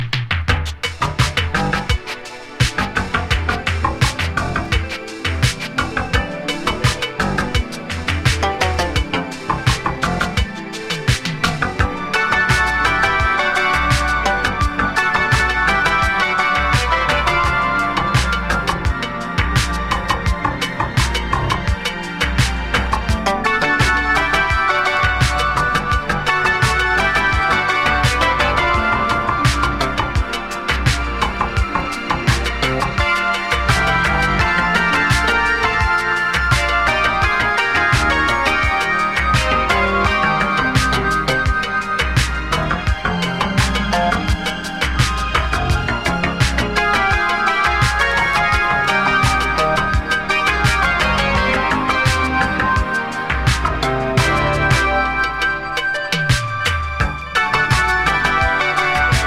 スムース・バレア・シンセ